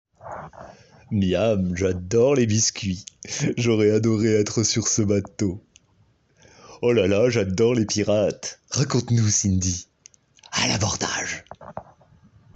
voix personnage animation 2